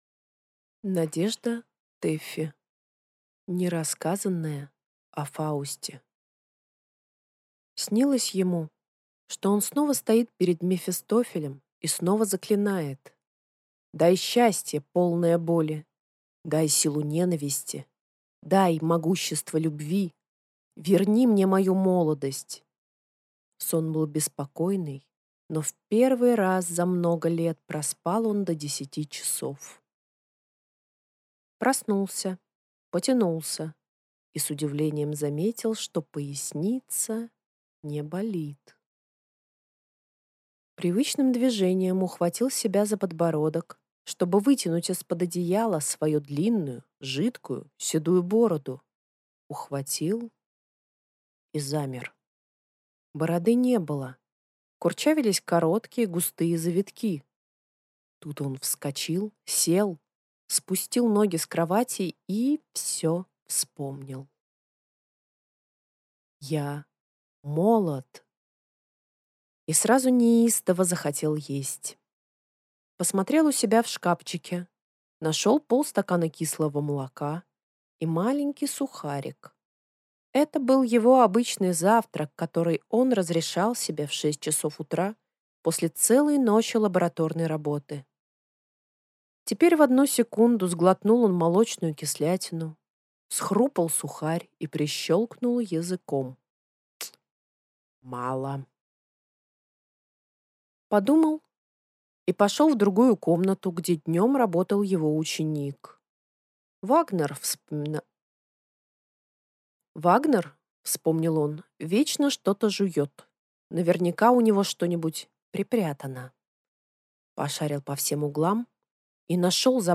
Аудиокнига Нерассказанное о Фаусте | Библиотека аудиокниг